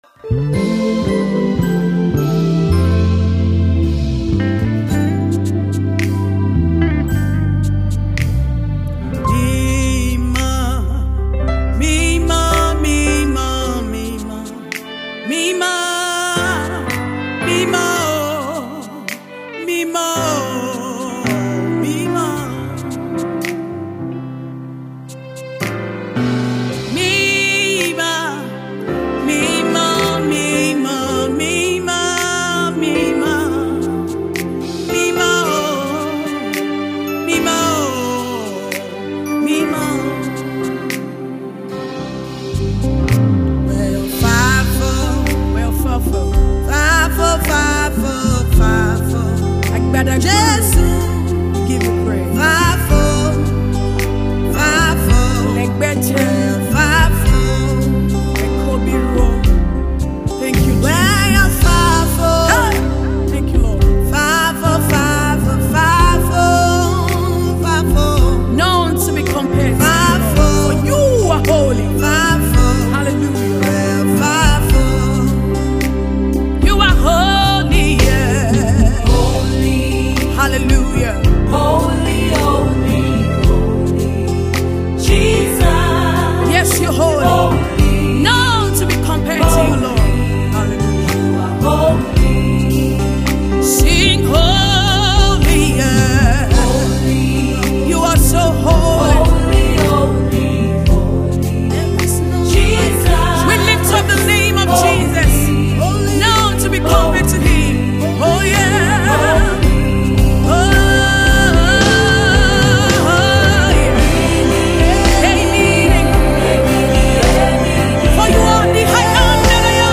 gospel music twin and versatile praise/worship leaders
a prolific gospel producer with anointed skills.